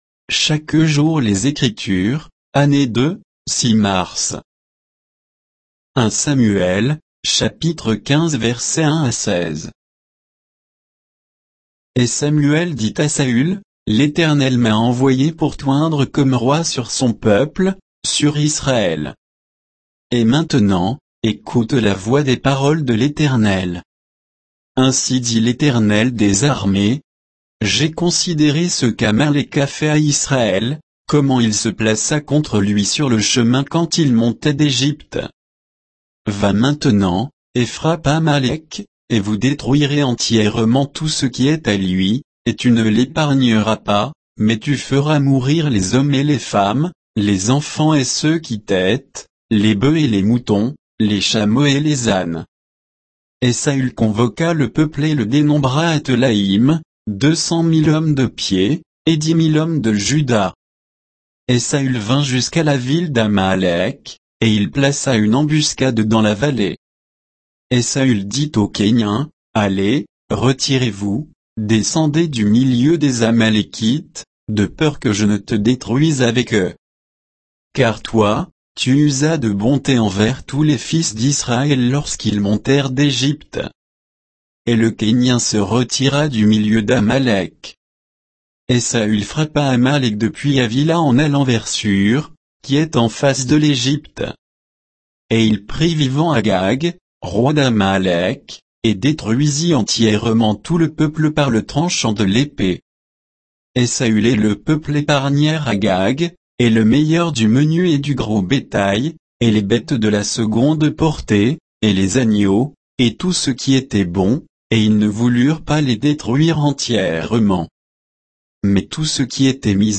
Méditation quoditienne de Chaque jour les Écritures sur 1 Samuel 15